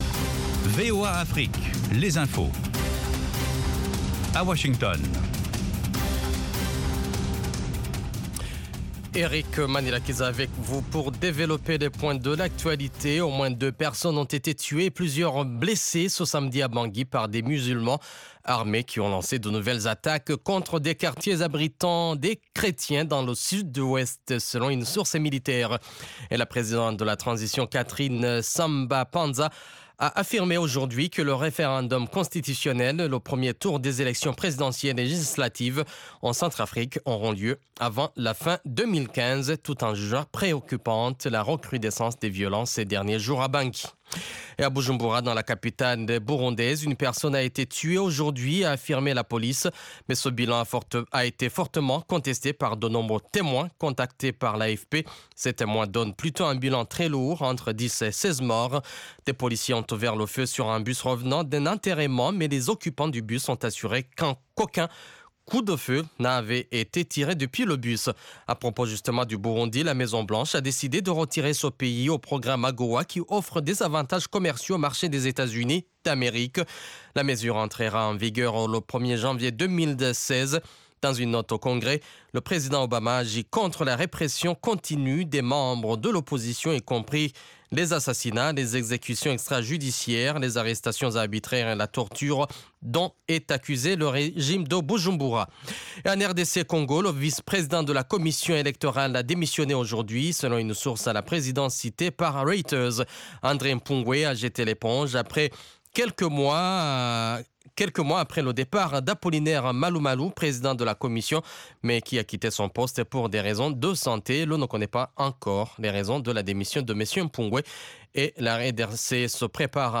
Bulletin
5min Newscast